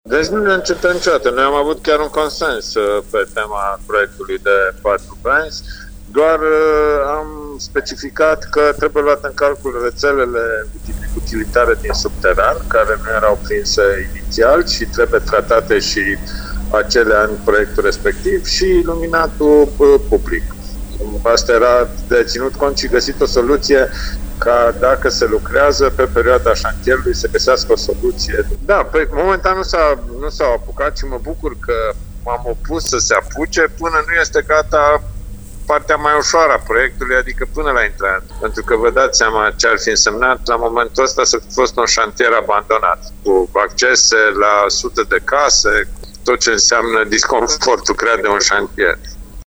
Primarul comunei Dumbrăvița Horia Bugărin, spune că nu există niciun conflict cu Consiliul Județean Timiș, doar că problema nu o reprezintă numai stâlpii.